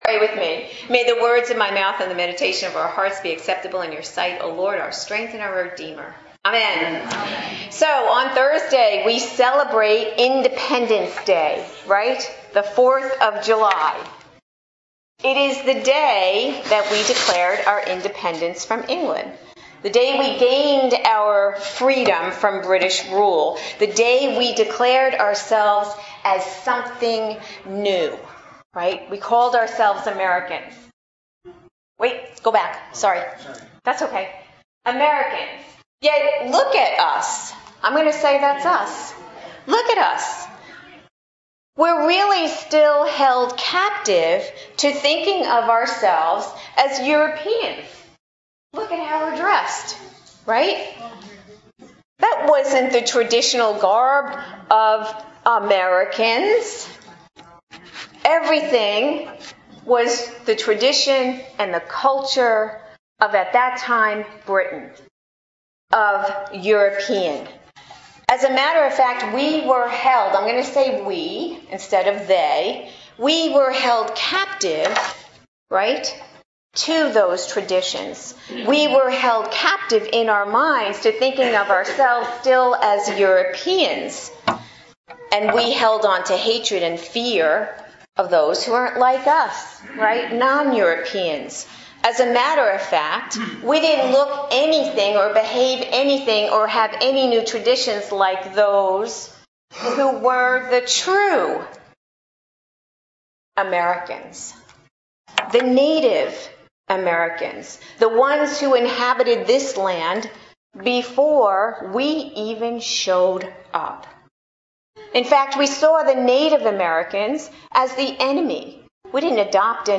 Worship
Adult Sermons